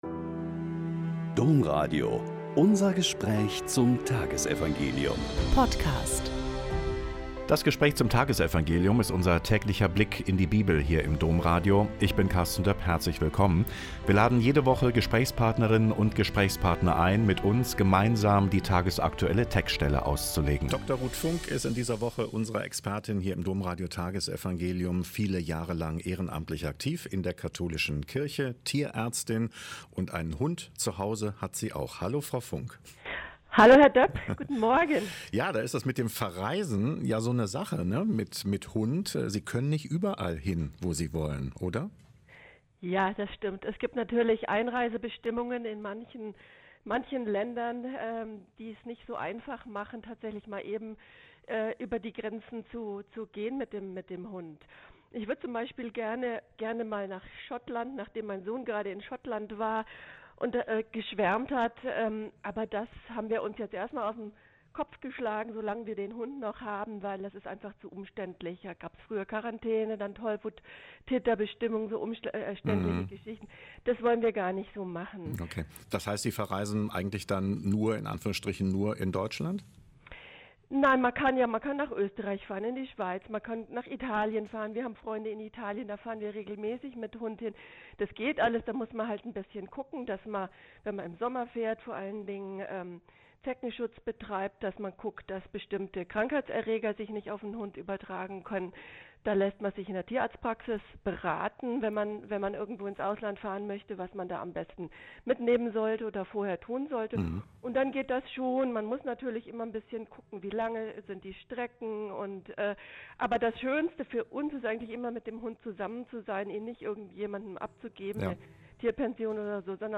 Mk 6,45-52 - Gespräch